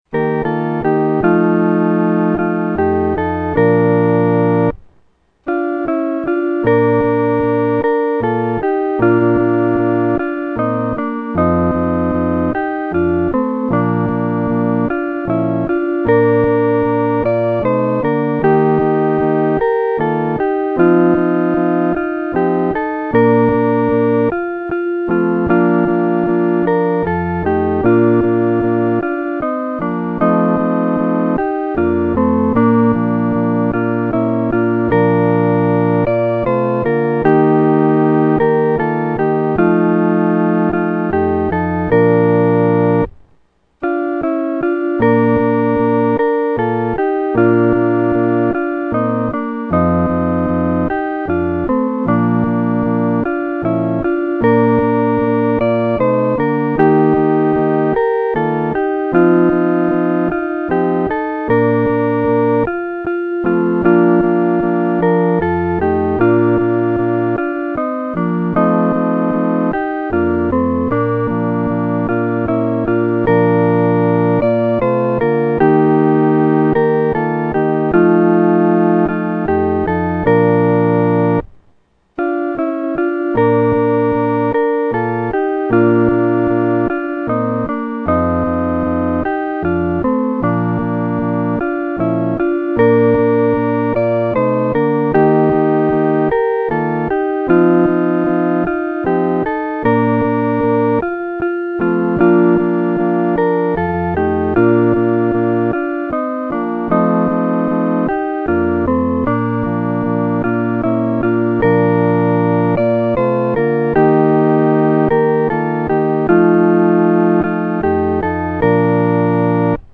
合奏（四声部）